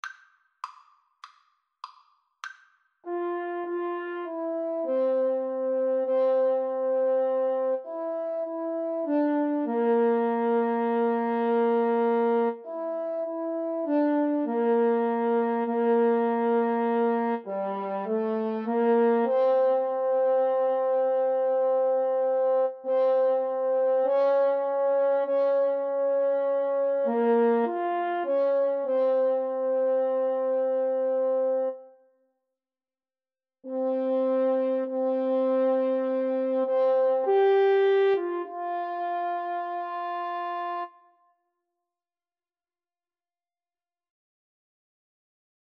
Espressivo
Arrangement for French Horn Duet